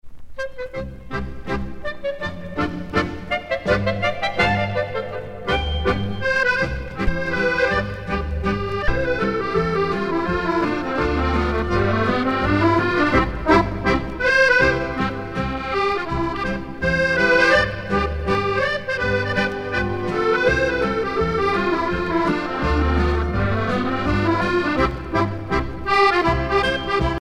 danse : hornpipe